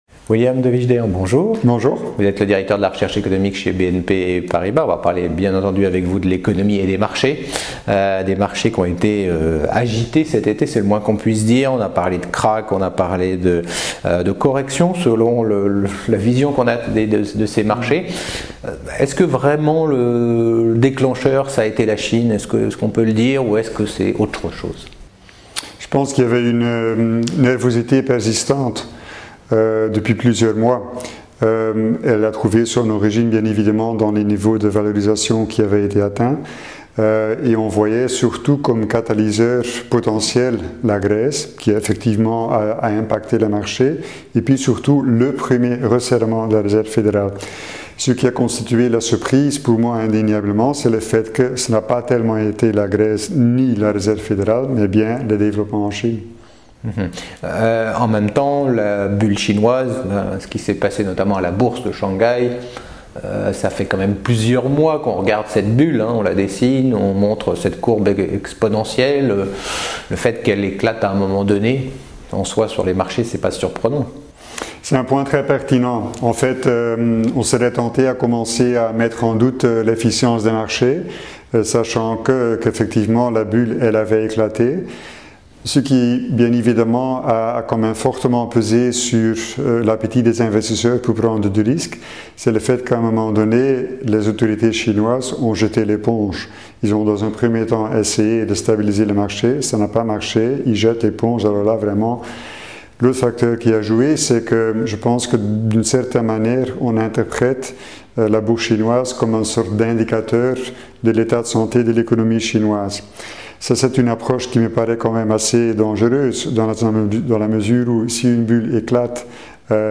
La Bourse et la Vie TV L'info éco à valeur ajoutée